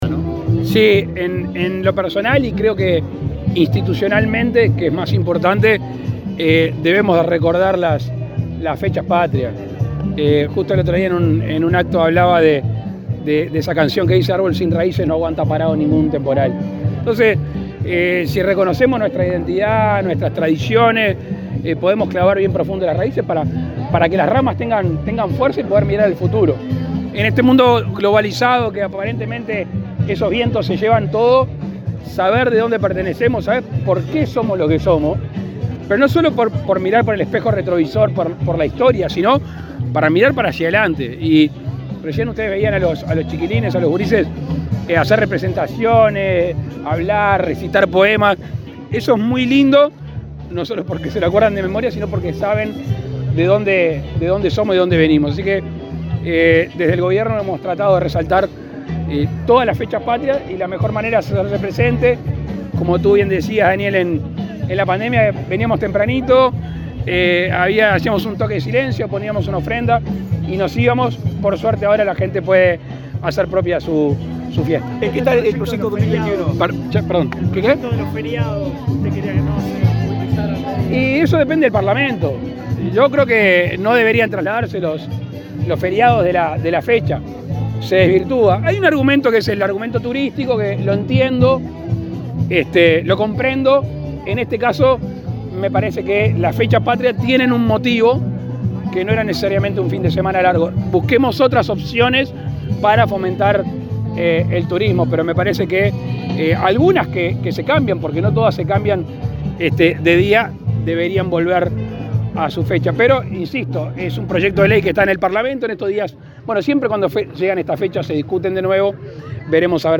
Declaraciones del presidente Lacalle Pou a la prensa
Luego dialogó con la prensa.